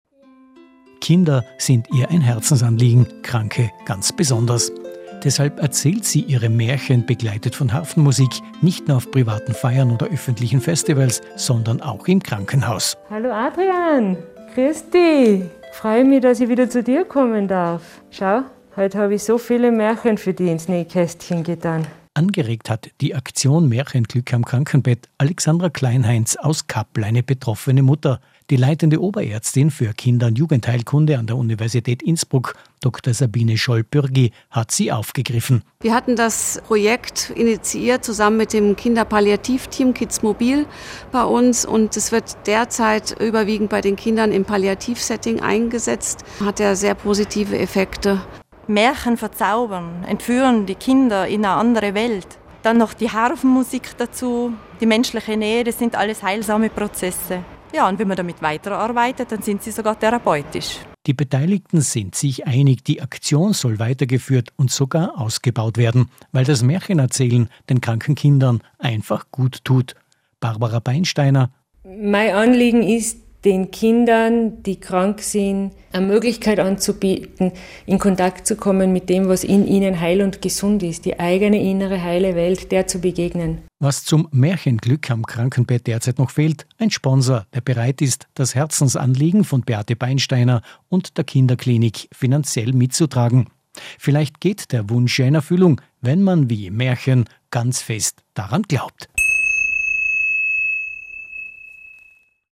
In diesem kurzen Video und im Radiobeitrag können Sie einen Eindruck von dem Projekt gewinnen:
ORF-Beitrag-Maerchenglueck-am-Krankenbett-Juni-2021.mp3